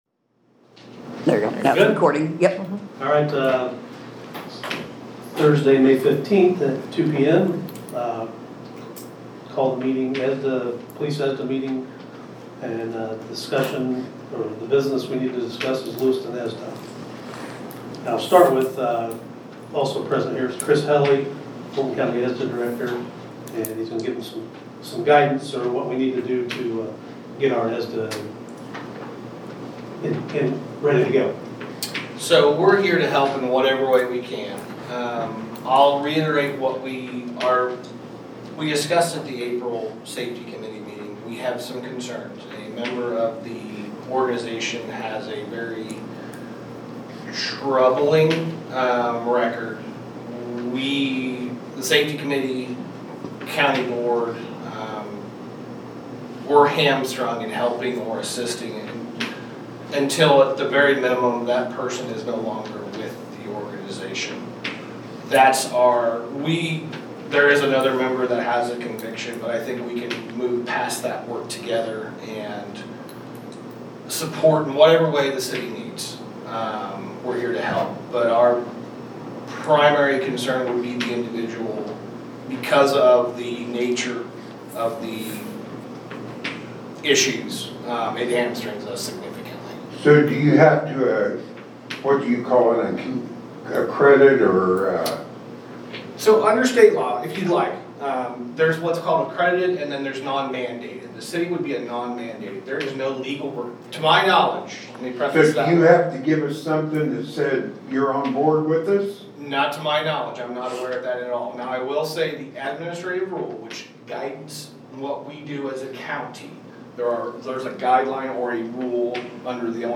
April 22nd, 2025 City Council Meeting Audio